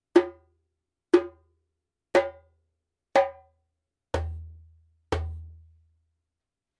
Djembe made in Mali
さすがに際立つような音量は出ませんが、鳴らしやすく、表現力十分です。
ジャンベ音